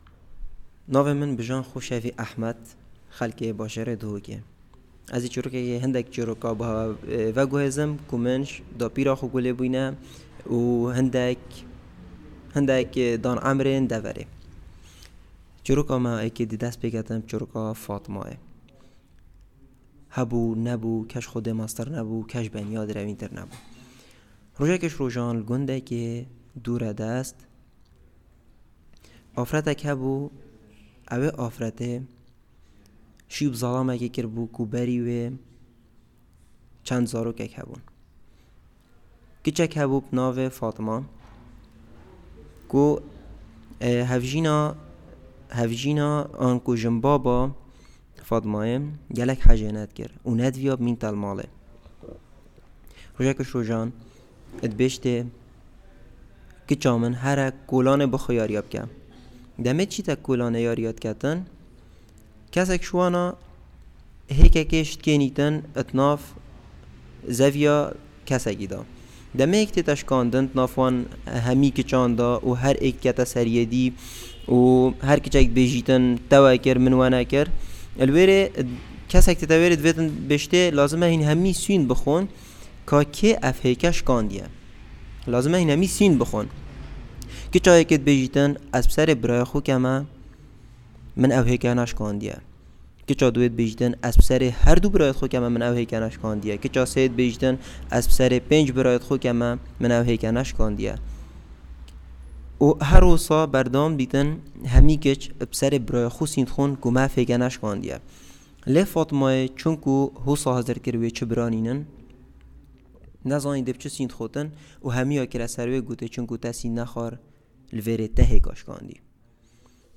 fieldwork 2021